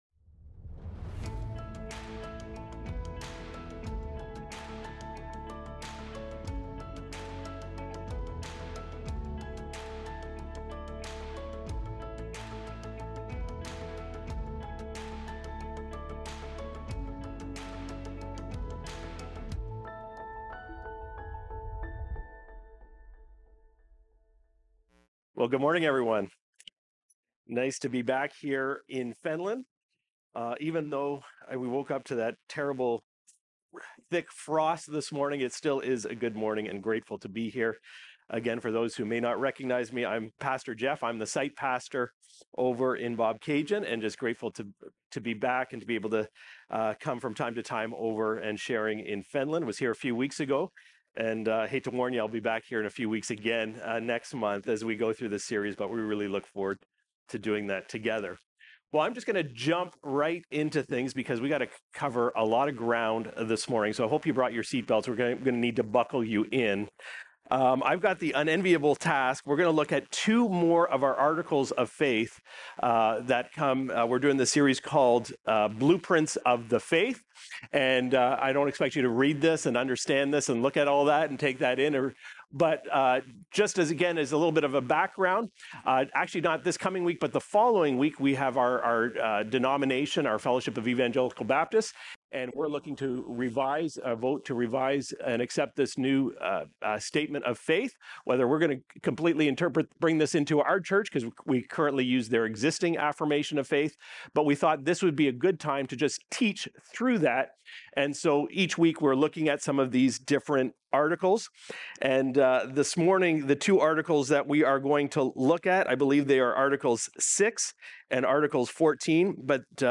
Recorded Sunday, October 26, 2025, at Trentside Fenelon Falls.